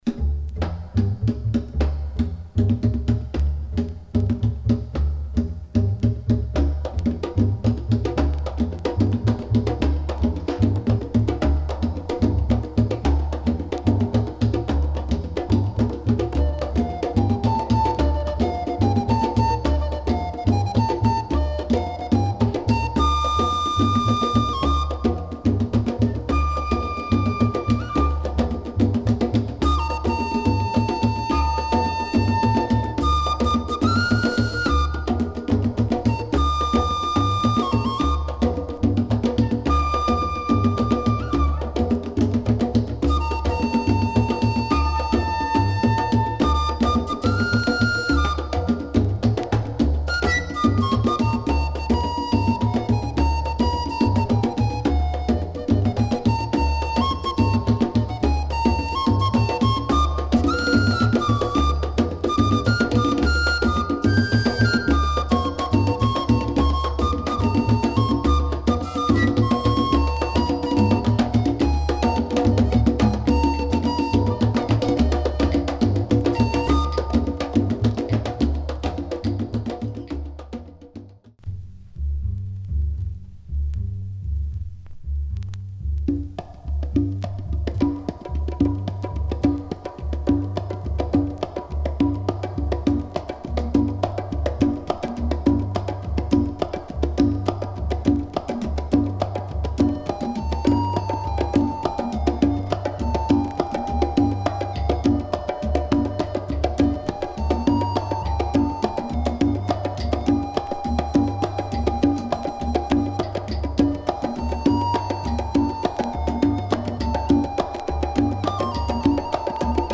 A great afro jazz album, now getting harder to find.